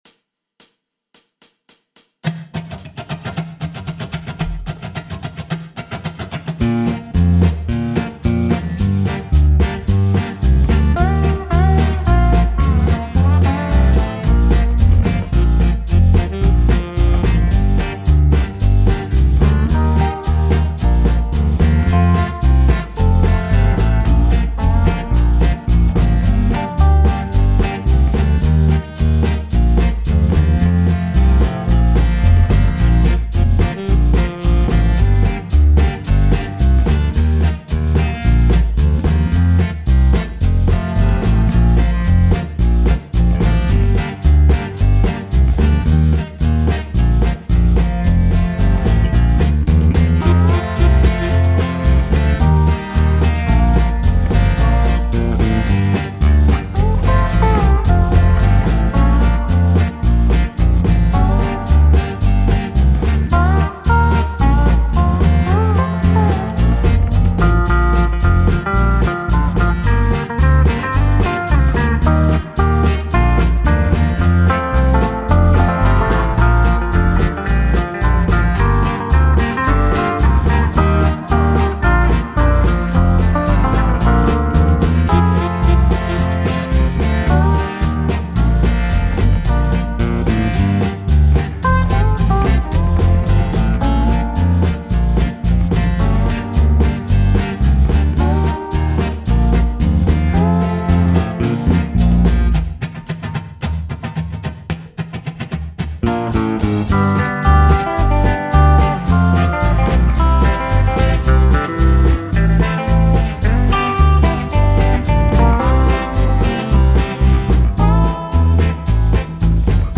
for the Bass Guitar.